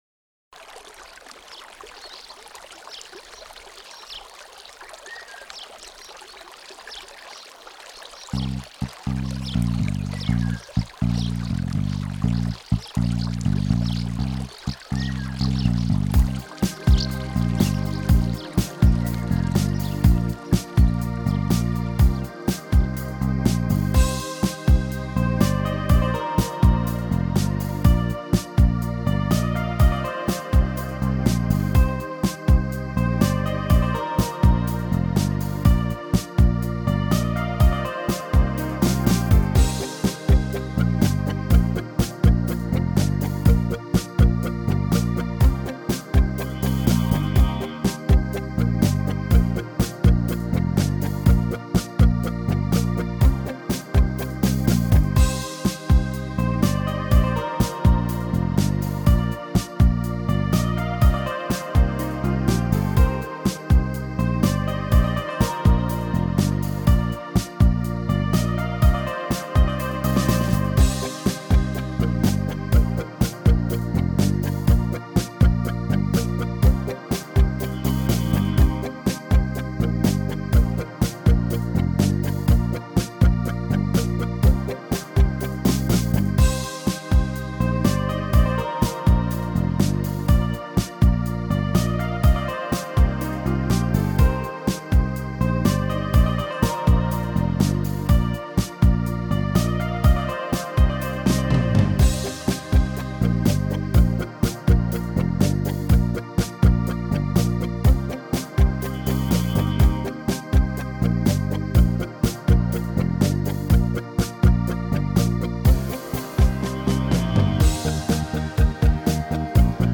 karaoke feldolgozása